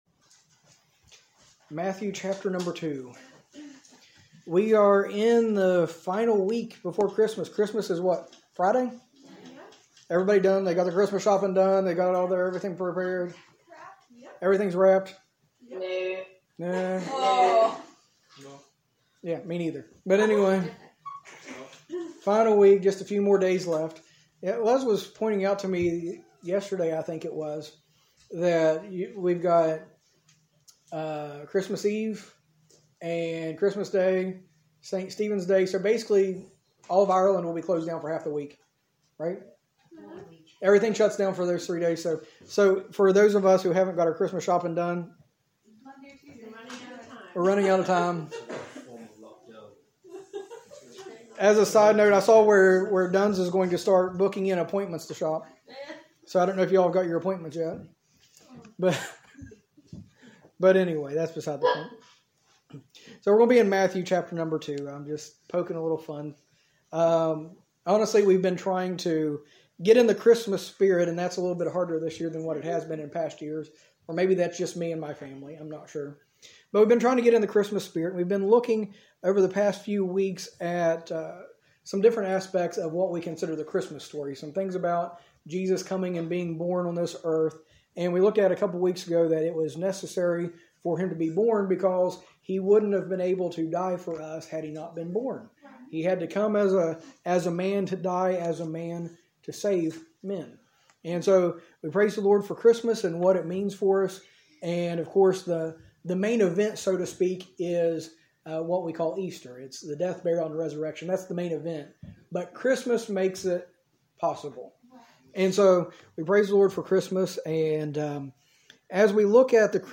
A message from the series "Series Breaks."